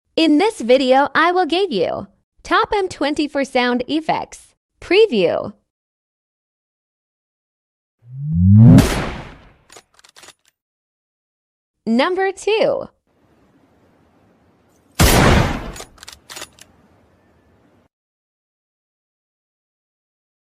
M24 sound effects || for sound effects free download